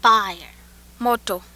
This is an example of the /o/ sound in Swahili. This snippet is taken from a larger recording found in Word List 3 of the UCLA Phonetics Lab Archive.
Swahili - o.wav